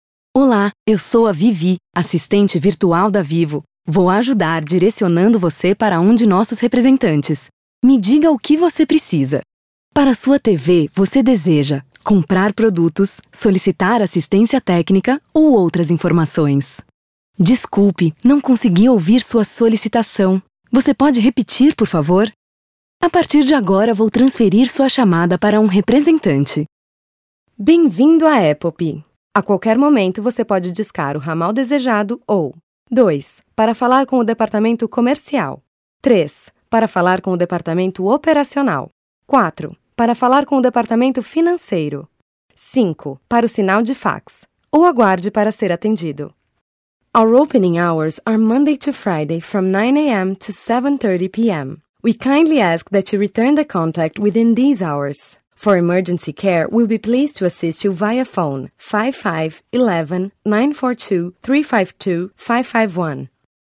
Feminino